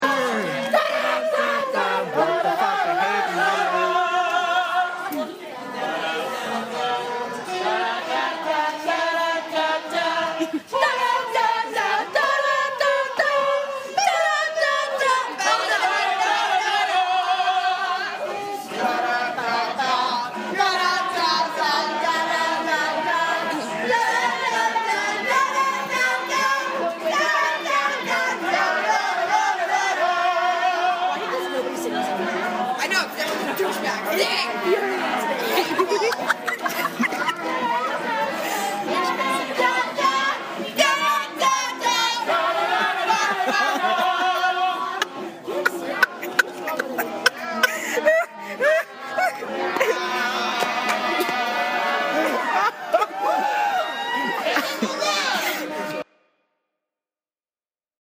Location: At Cabaret, in the Spiegel Theater this past Wednesday, the 17th of April.